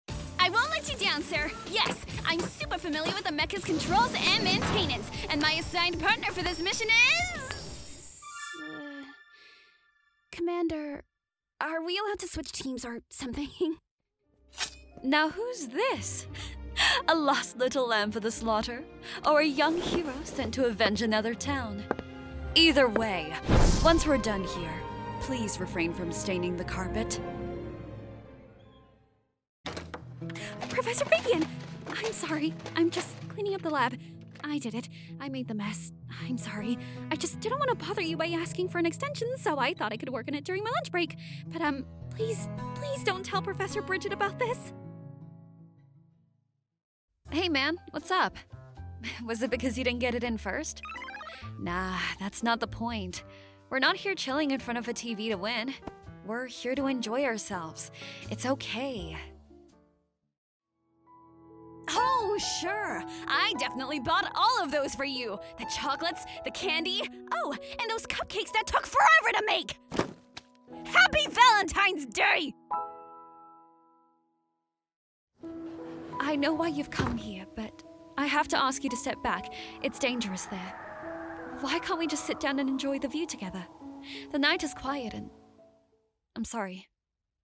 character - british accent
character - us accent
character - child
character - teenager